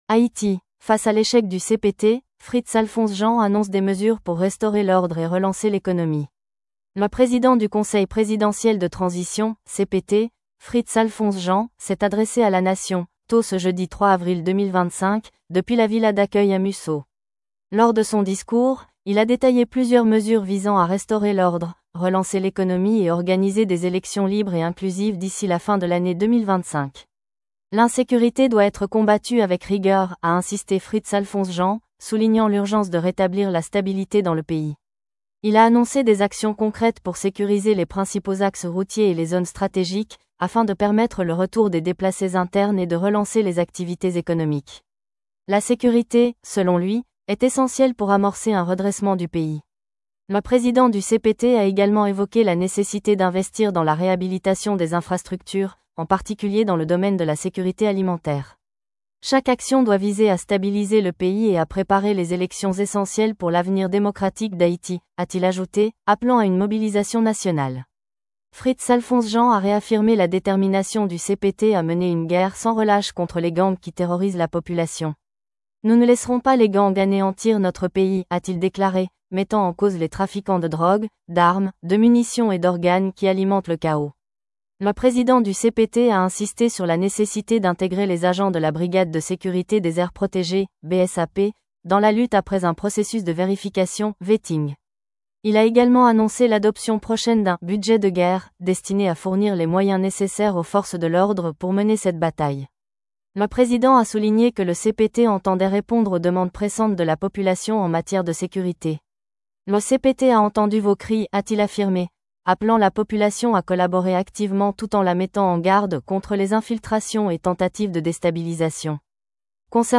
Le Président du Conseil Présidentiel de Transition (CPT), Fritz Alphonse Jean, s’est adressé à la nation, tôt ce jeudi 3 avril 2025, depuis la Villa d’Accueil à Musseau. Lors de son discours, il a détaillé plusieurs mesures visant à restaurer l’ordre, relancer l’économie et organiser des élections libres et inclusives d’ici la fin de l’année 2025.